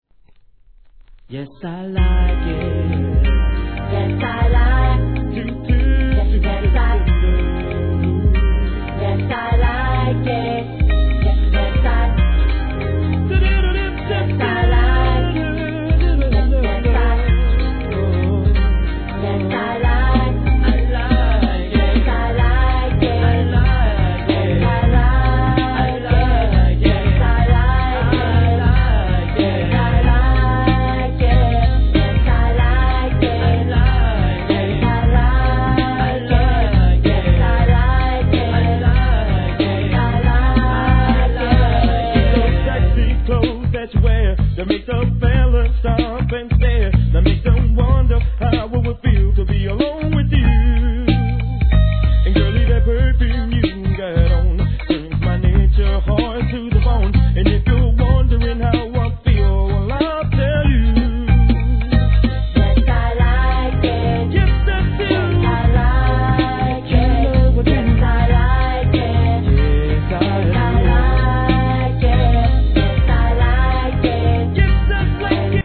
HIP HOP/R&B
1994年の激インディーR&B物!!